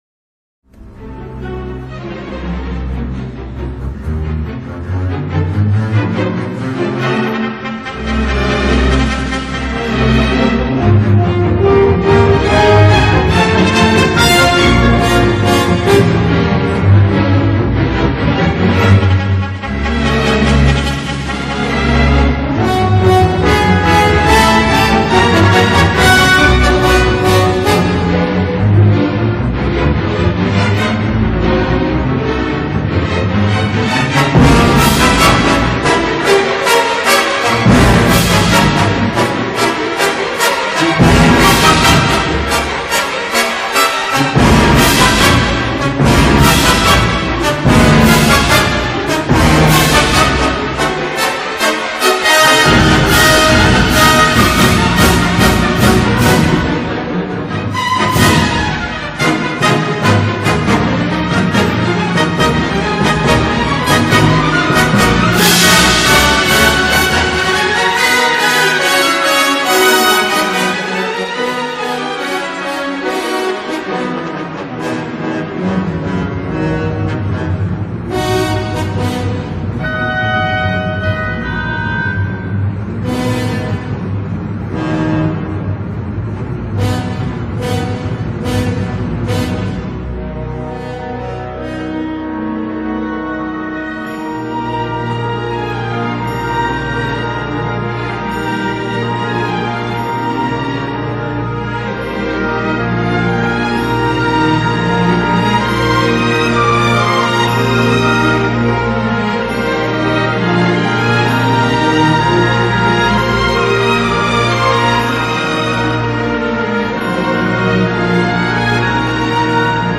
U hoort zo dadelijk een fragment uit een klassieke ouverture.